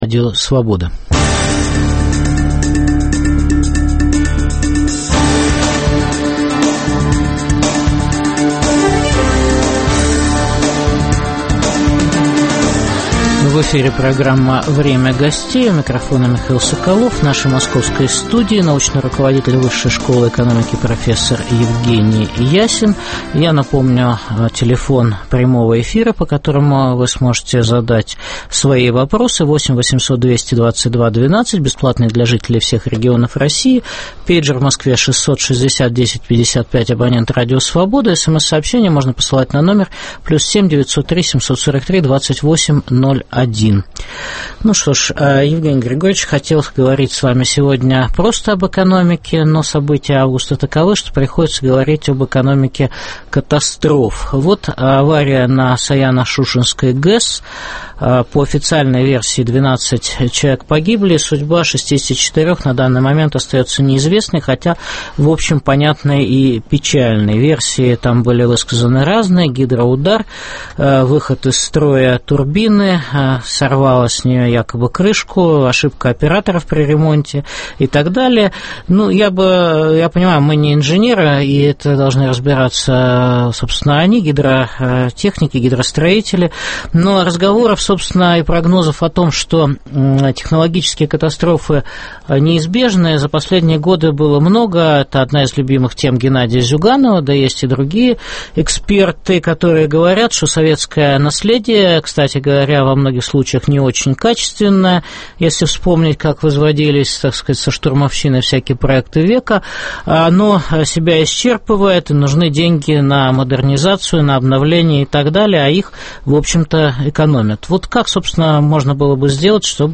Какой осенний урожай даст антикризисная политика российской власти? В программе "Время гостей" выступает научный руководитель Высшей школы экономики профессор Евгений Ясин.